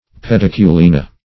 Search Result for " pediculina" : The Collaborative International Dictionary of English v.0.48: Pediculina \Pe*dic`u*li"na\, n. pl.